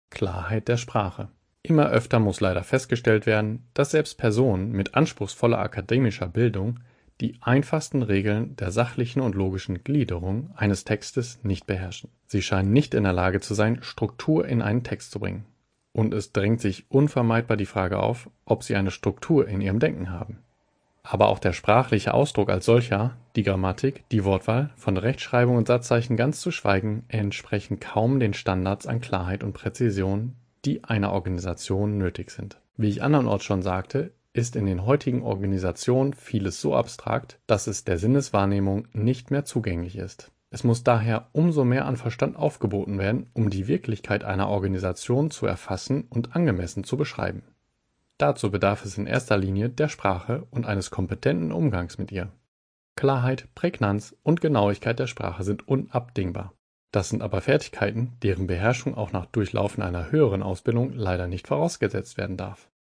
Vorgelesen von echten Menschen – nicht von Computern.